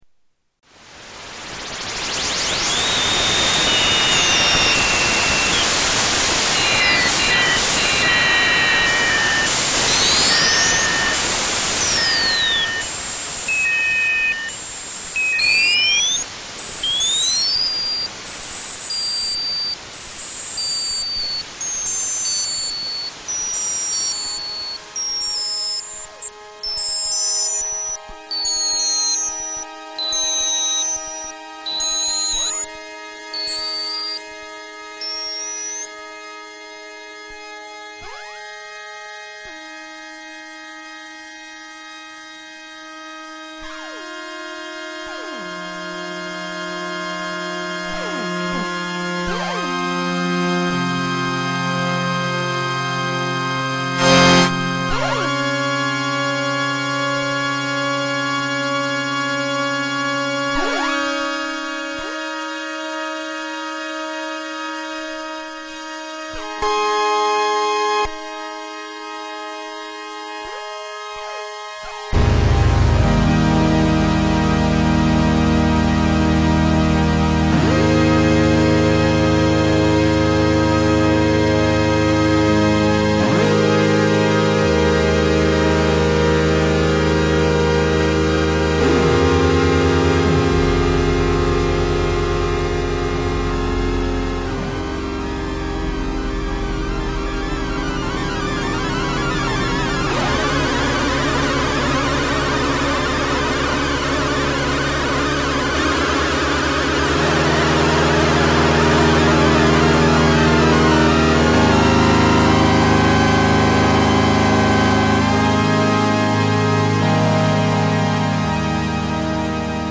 Jomox Sunsyn test 2
Tags: Sound Effects JoMoX Sounds JoMoX XBase AirBase